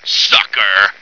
flak_m/sounds/male2/int/M2sucker.ogg at d2951cfe0d58603f9d9882e37cb0743b81605df2